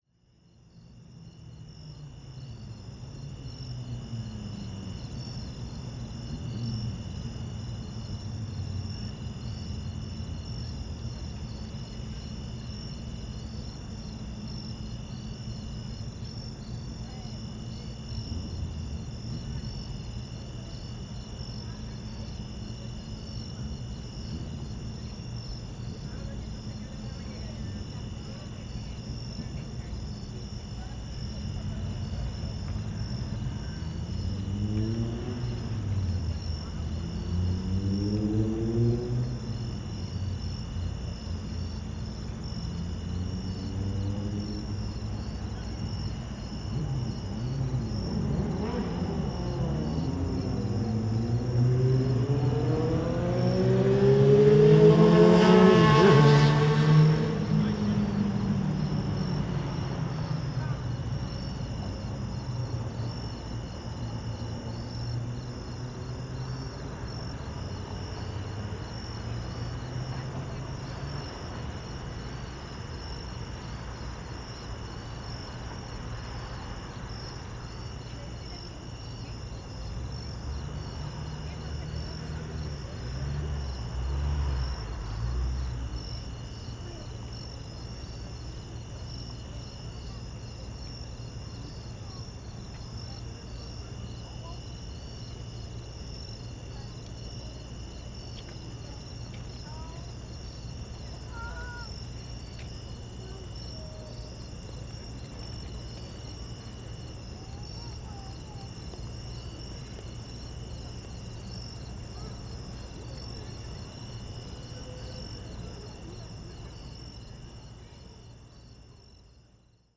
Recorded just outside Newport-Pavonia PATH station on a hot summer night in August. A lot of crickets, a few people and a prat on a motorcycle.
Ambisonic
Ambisonic order: F (4 ch) 1st order 3D
Microphone name: Soundfield ST250
Array type: Tetrahedral
Capsule type: sub-cardioid